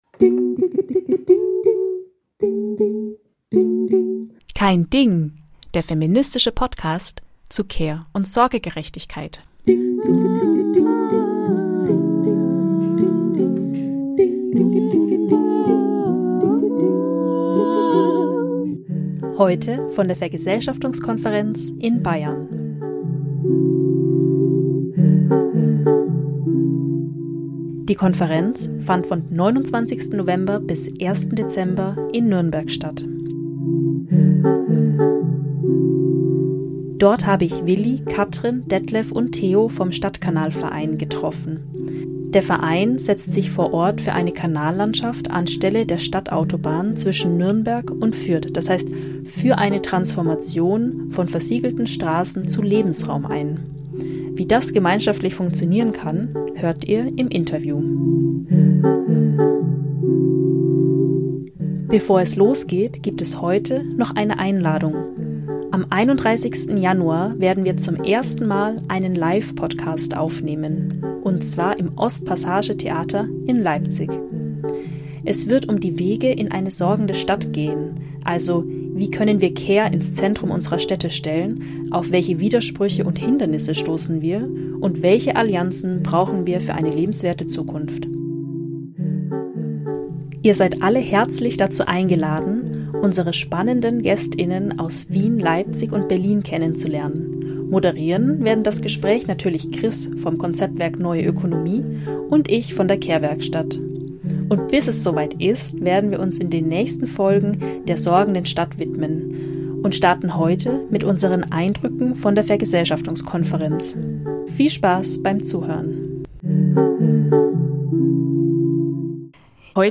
In dieser Folge nehmen wir euch mit zur Vergesellschaftungskonferenz Bayern. Die Konferenz fand von 29. November bis 01.Dezember in Nürnberg statt
Der Verein setzt sich vor Ort für eine Kanallandschaft anstelle der Stadtautobahn zwischen Nürnberg und Fürth, d.h. für eine Transformation von versiegelten Straßen zu Lebensraum ein. Wie das gemeinschaftlich funktionieren kann, hört ihr im Interview.